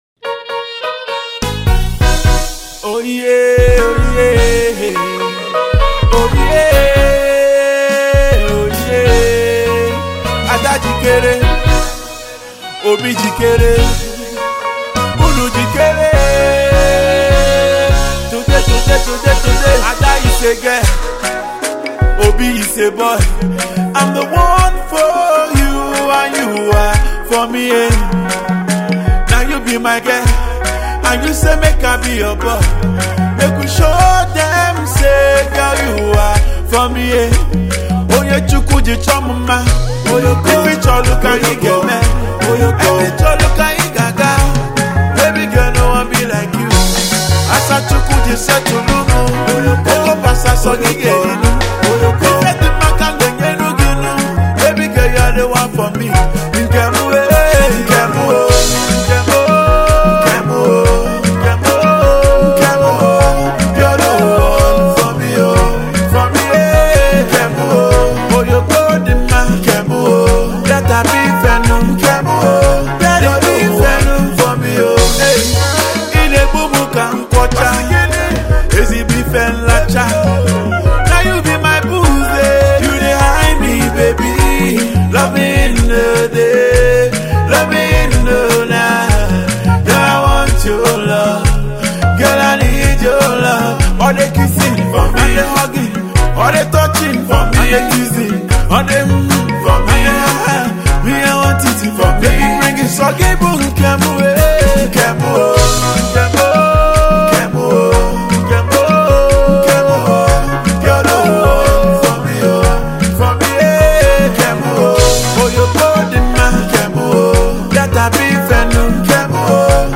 Nigerian contemporary highlife